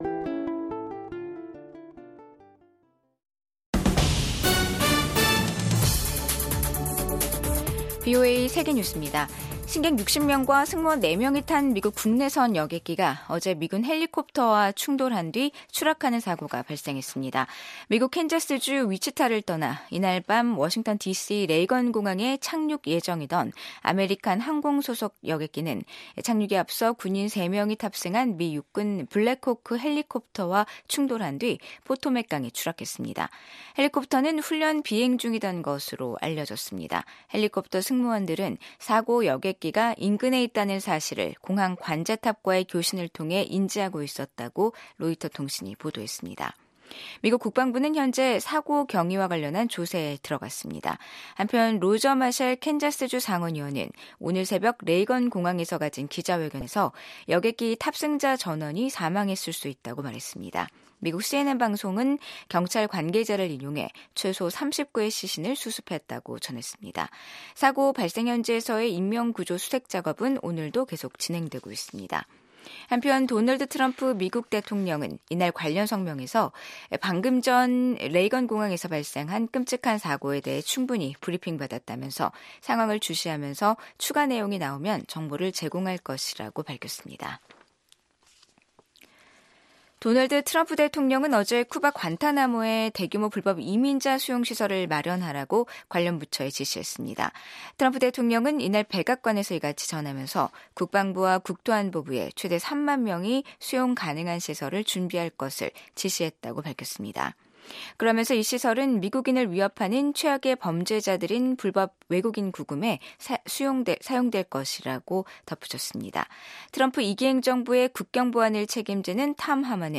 VOA 한국어 간판 뉴스 프로그램 '뉴스 투데이', 2025년 1월 30일 3부 방송입니다. 미국 의회에서 한반도 문제를 담당할 소위원회 구성이 완료된 가운데 한반도 정책에는 변화가 없을 거란 전망이 나옵니다. 도널드 트럼프 미국 대통령이 동맹과의 미사일 방어를 강화하라고 지시한 데 대해 전문가들은 미한 통합미사일 방어 구축 의지를 확인한 것으로 해석했습니다.